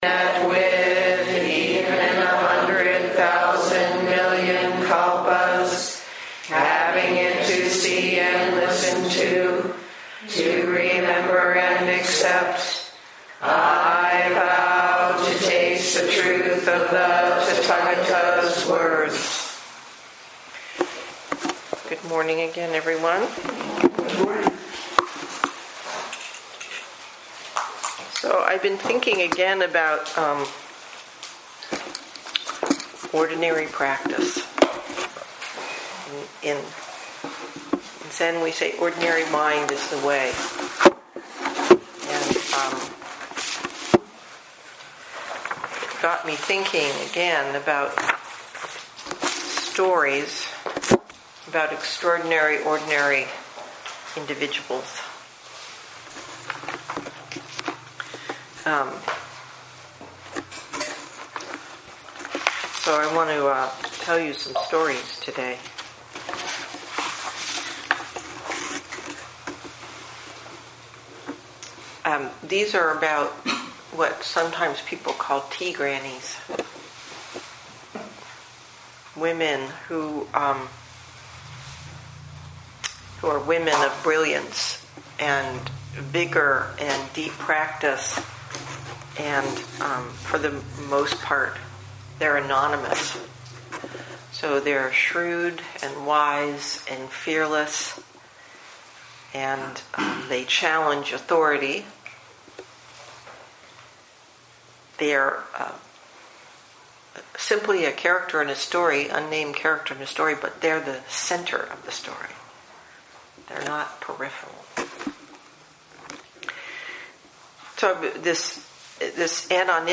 2018 in Dharma Talks